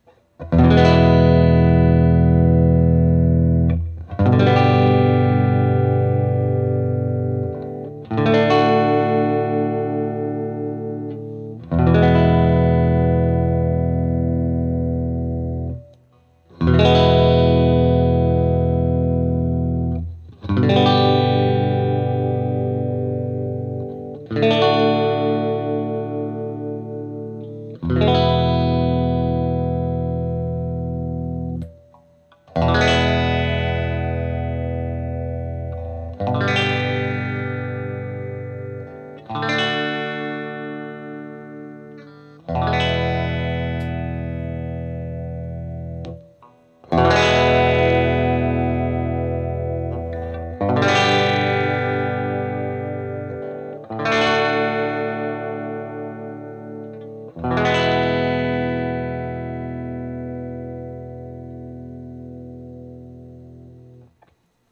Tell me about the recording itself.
Each recording goes though all of the pickup selections in the order: neck, both (in phase), both (out of phase), bridge. I recorded the difference with the second set of recordings with the pickups farther from the strings.